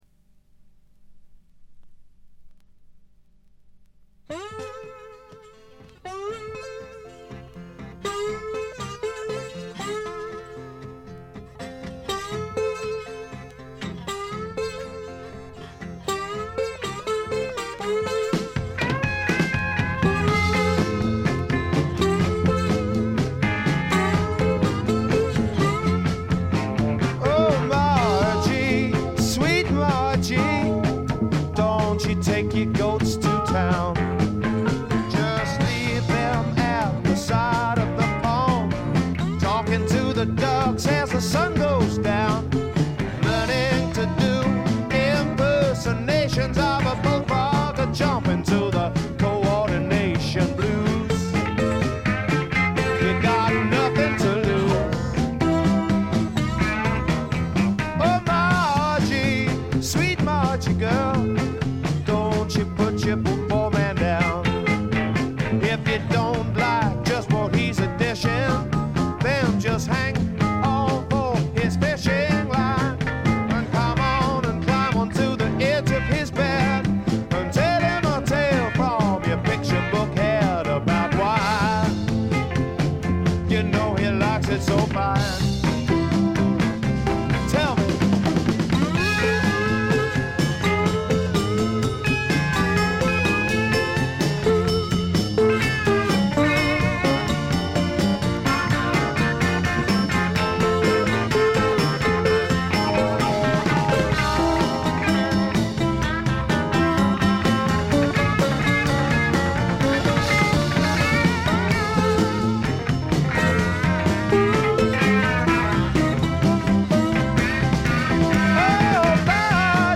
ほとんどノイズ感無し。
というわけでスモーキーなヴォーカルが冴える霧の英国シンガー・ソングライターの金字塔作品です。
試聴曲は現品からの取り込み音源です。